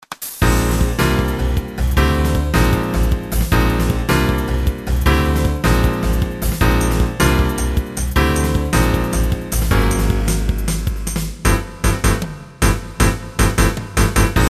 しかしGSの一部の音が出ないのが残念。
ステレオ、16ビット　エフェクト・リバーブ、コーラス、コンプレッサ